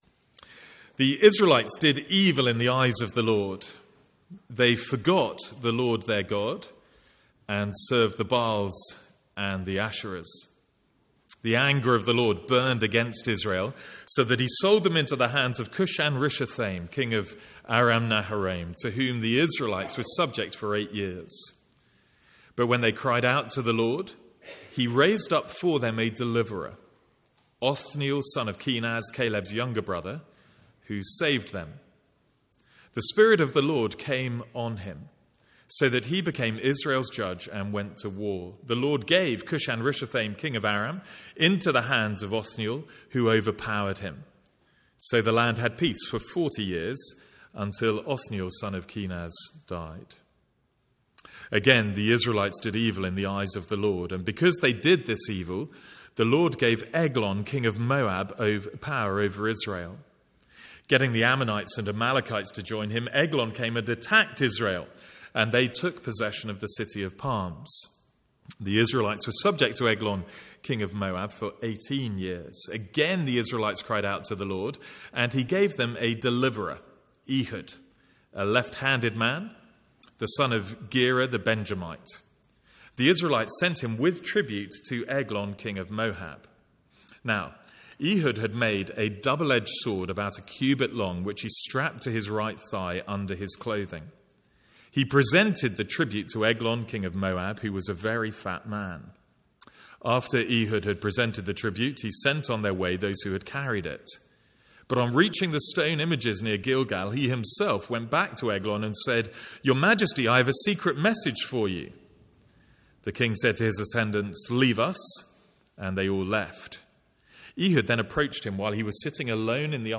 From the Sunday morning series in Judges.